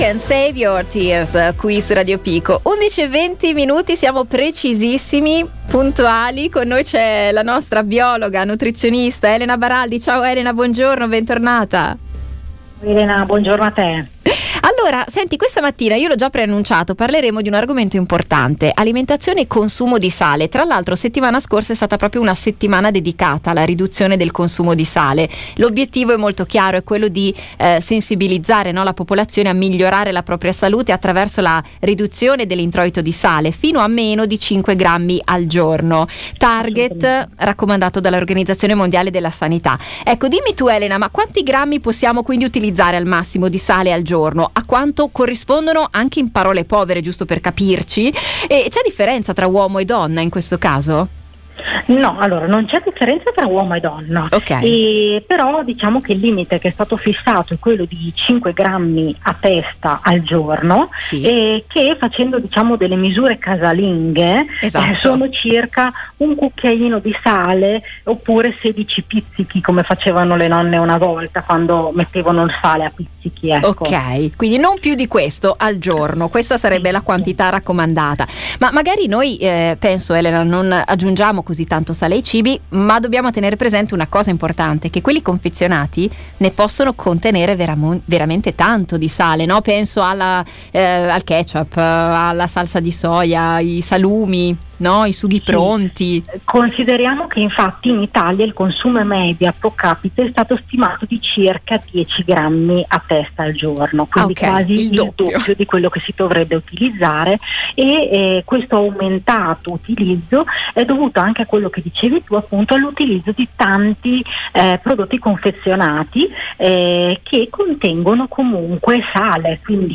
Nell’intervista trovate anche qualche ricettina in cui non viene utilizzato il sale.